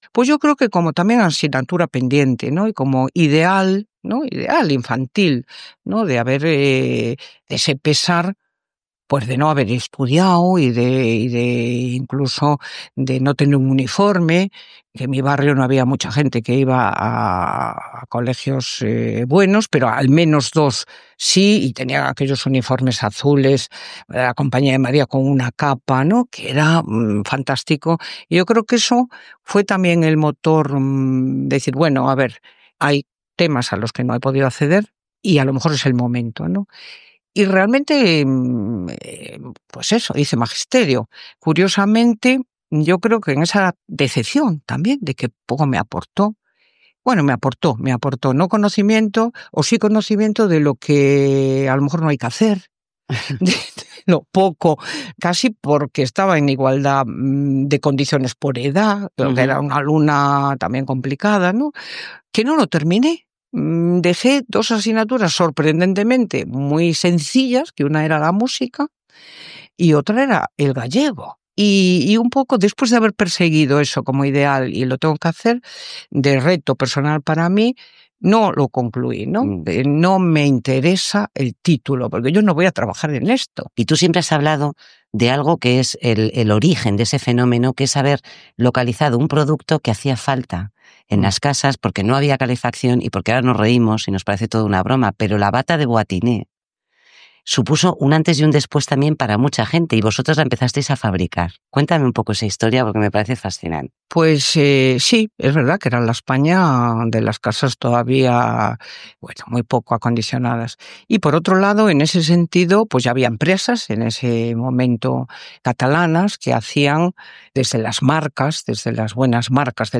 Entrevista a l'empresària Rosalía Mera, cofundadora de l'empresa multinacional Inditex. Parla dels seus estudis i de l'inici del negoci de bates que va crear a Galícia